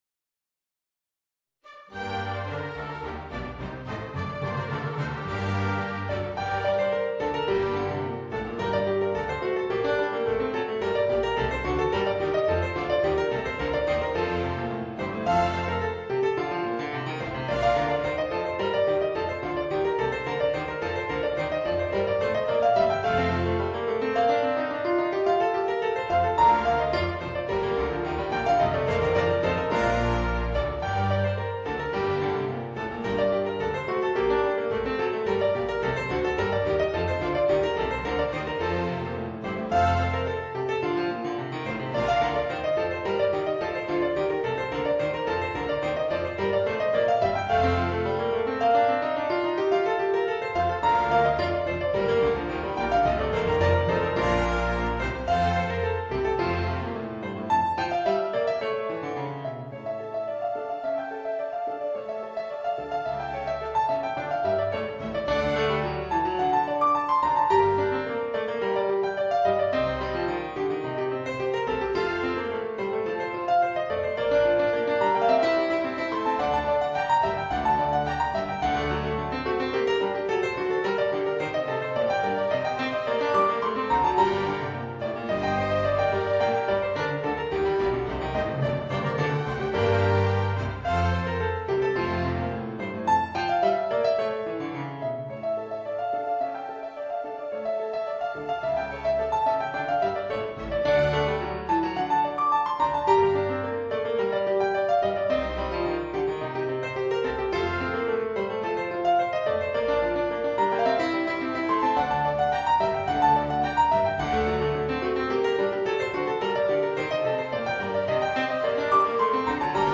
Voicing: Orchestra